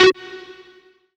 M57 LEADGTAR.wav